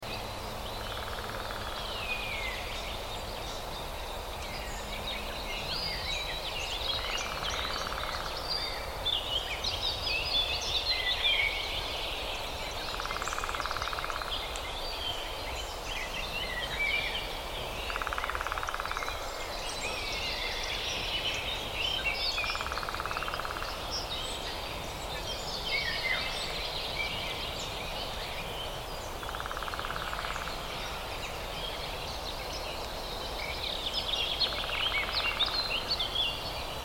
دانلود آهنگ جنگل 2 از افکت صوتی طبیعت و محیط
دانلود صدای جنگل 2 از ساعد نیوز با لینک مستقیم و کیفیت بالا
جلوه های صوتی